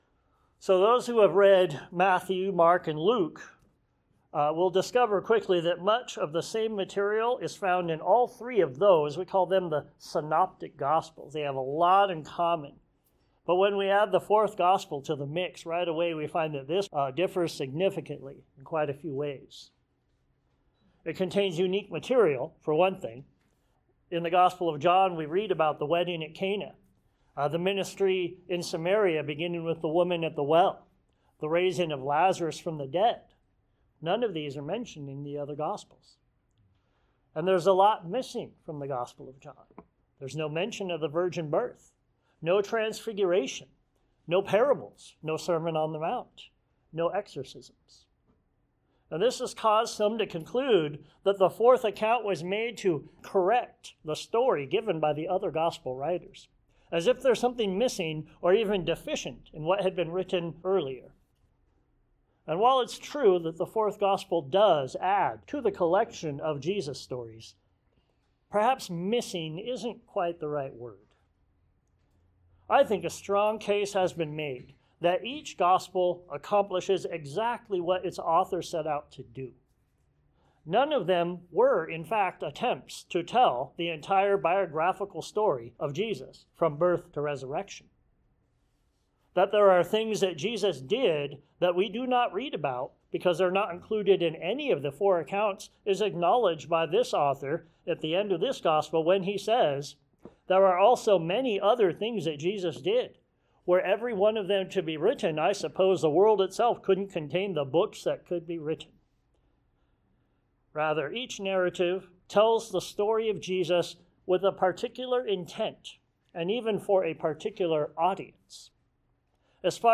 The Word Became Flesh John 1:1-18 Sermons Share this: Share on X (Opens in new window) X Share on Facebook (Opens in new window) Facebook Like Loading...